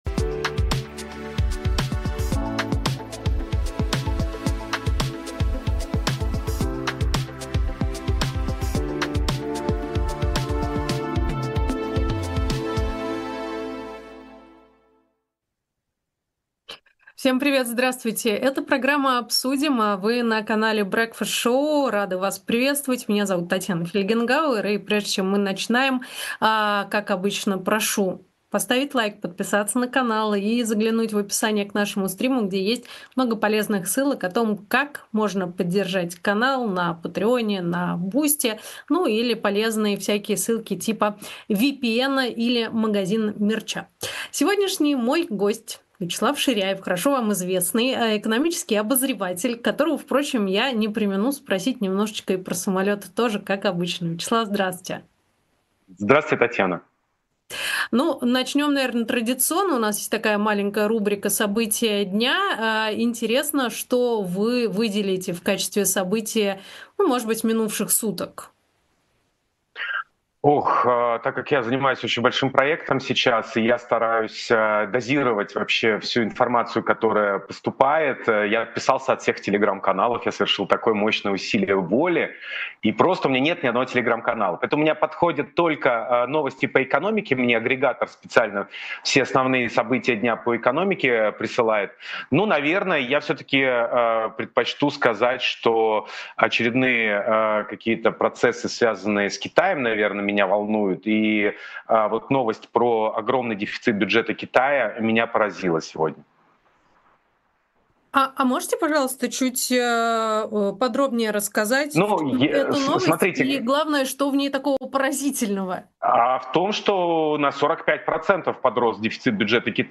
Ведёт эфир Татьяна Фельгенгауэр